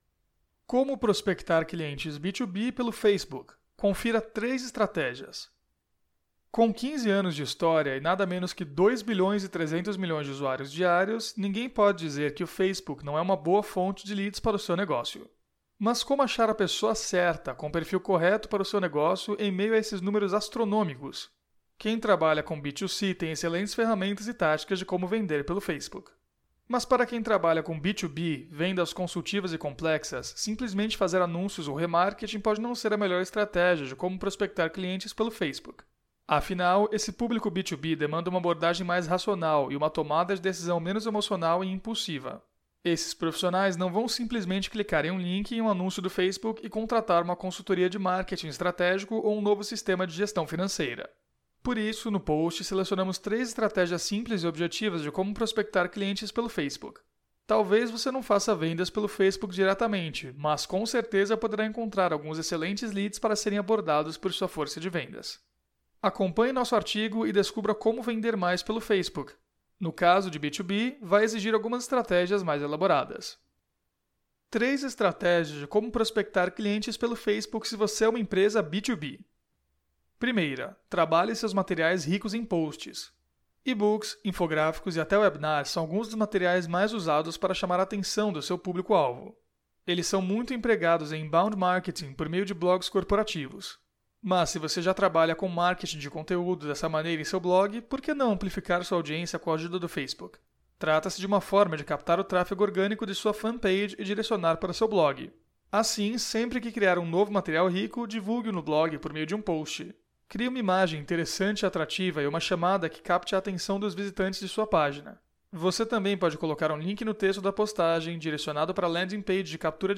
Aula 03
audio-aula-como-prospectar-clientes-pelo-facebook-confira-3-estrategias.mp3